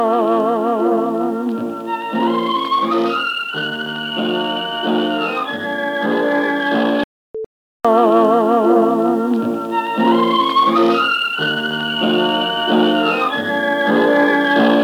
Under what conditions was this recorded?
Recording from "Mono" cassette tape The tape is monophonic recording. If you make a truly mono version you can hear the difference in the stereo image …